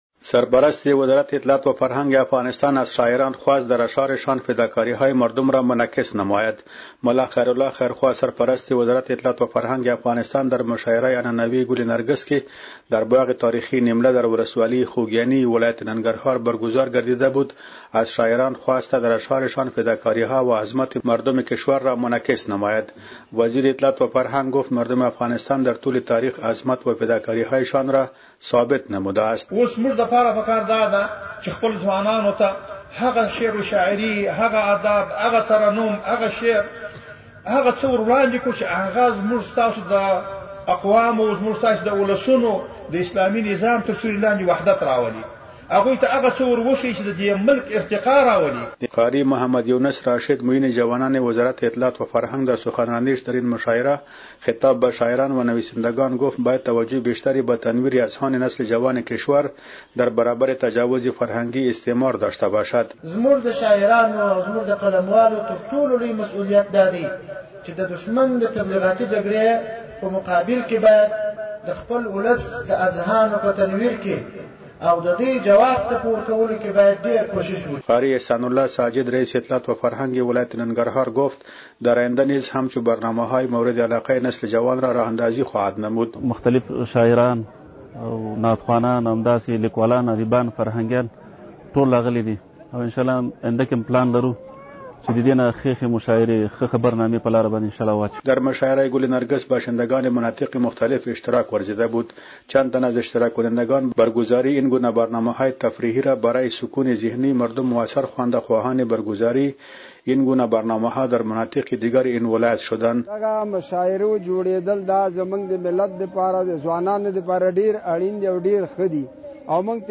خبر / فرهنگی